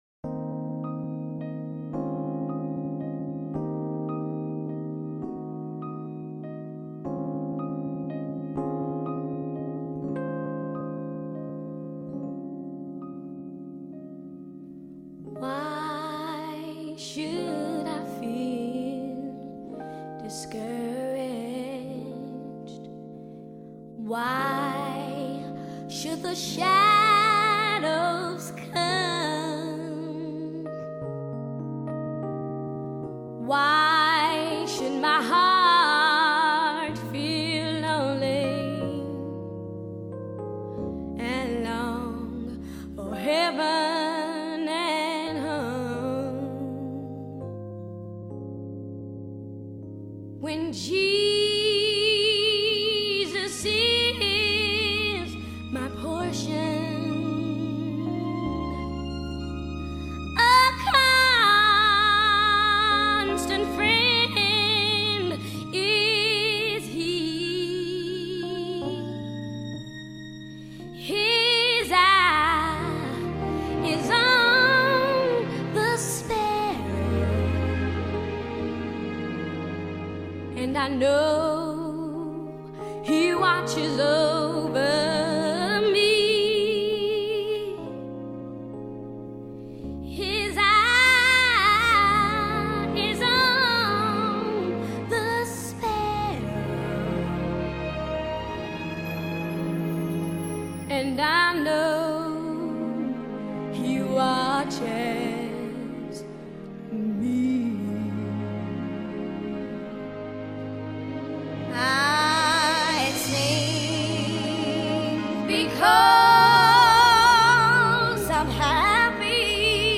Ab to A,